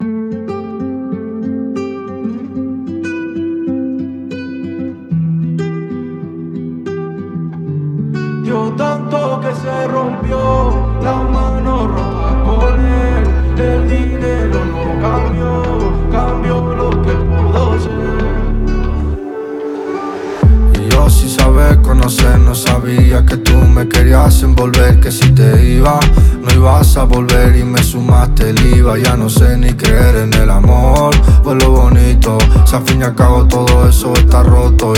Жанр: Рэп и хип-хоп / Альтернатива